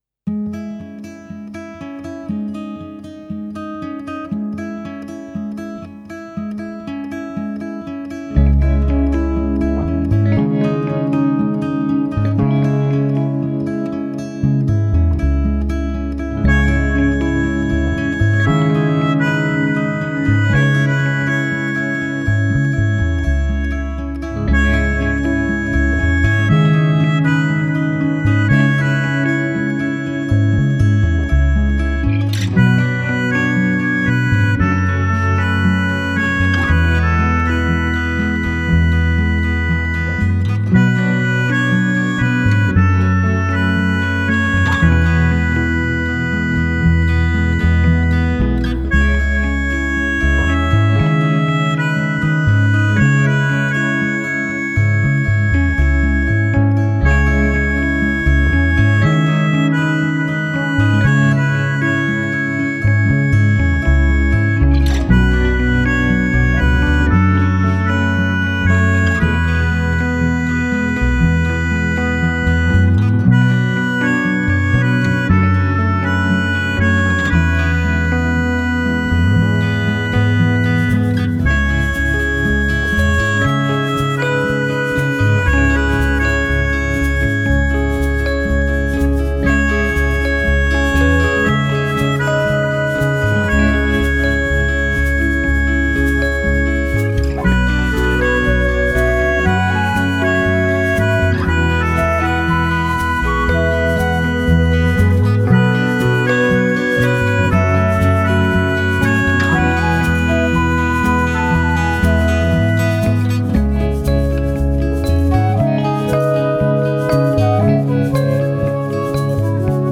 Genre: Indie, Alternative Rock, Original Soundtrack